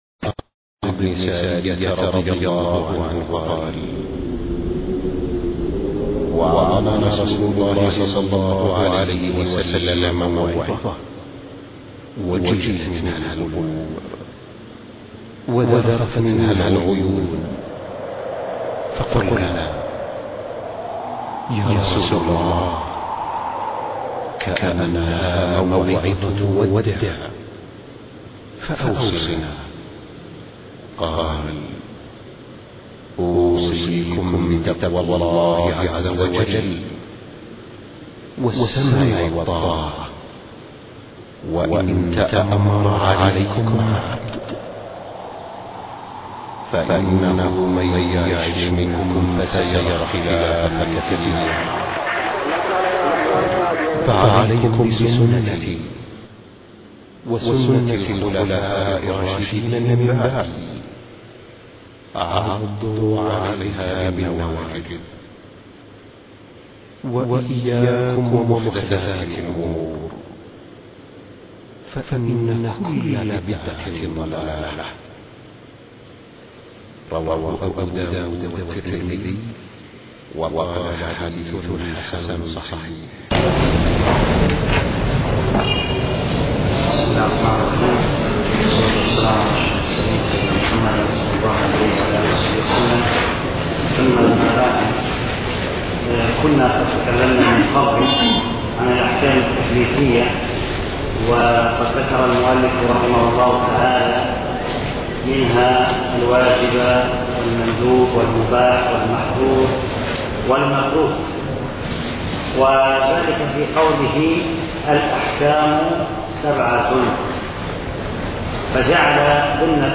شرح الورقات الدرس (5)